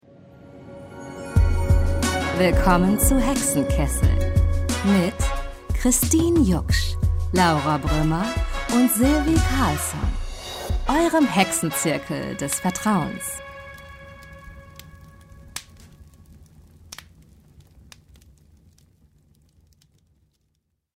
2020 - Introvoice - Hexenkessel